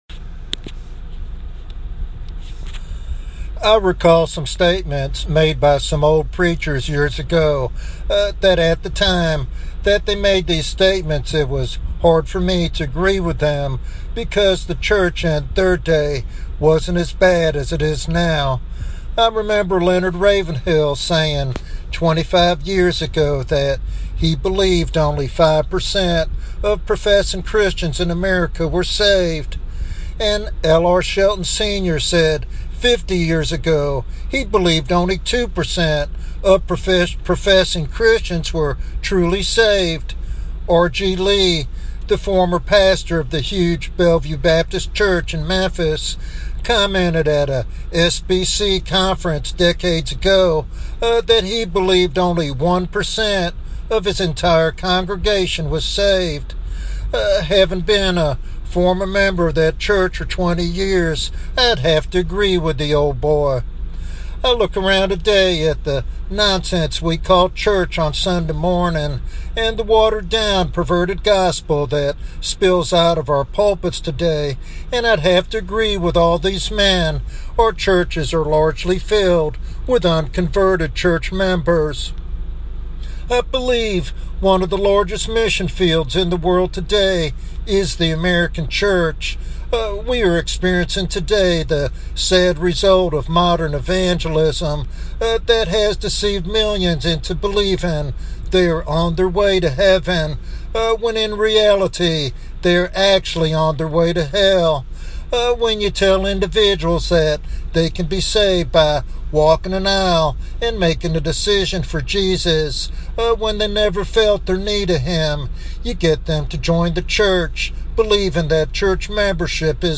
He calls believers to return to the pure gospel message that confronts sin and points to Christ as the only remedy. This sermon serves as a sobering reminder that the gospel is truly for the needy and calls for genuine faith and transformation.